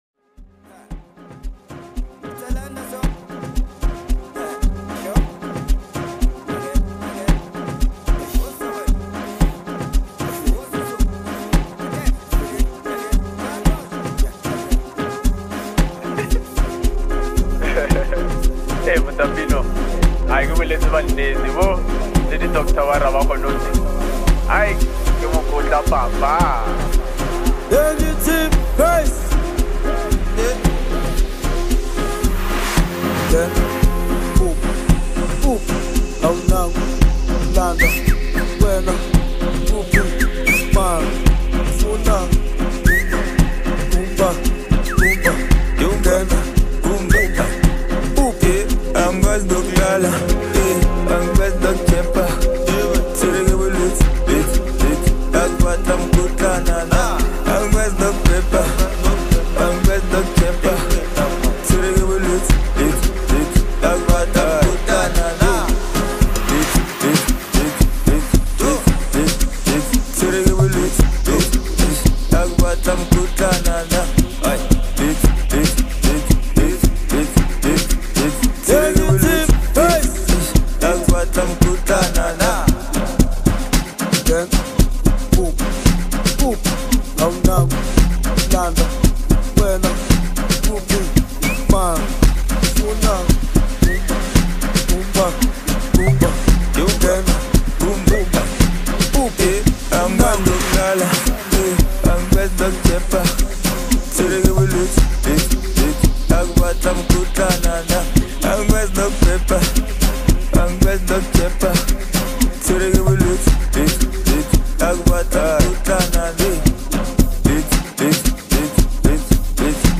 catchy rhythm, smooth vibe